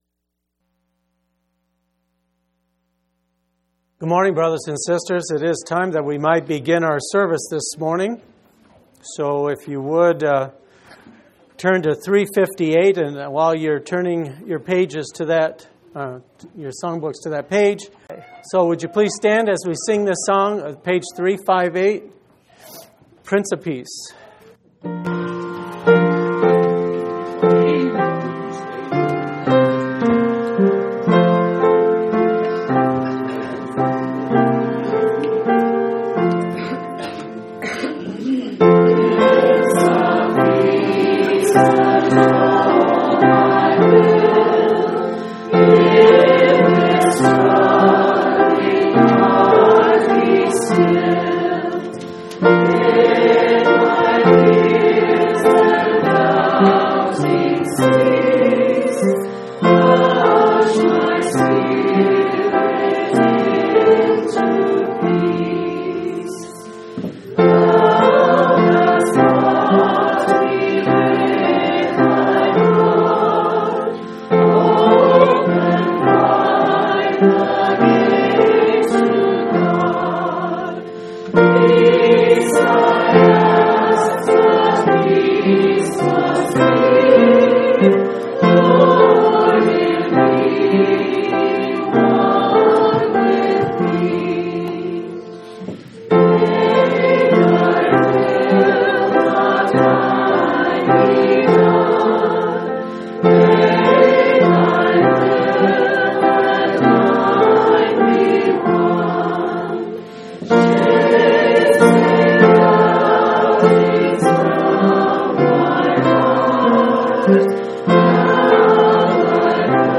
1/11/2004 Location: Phoenix Local Event
audio-sermons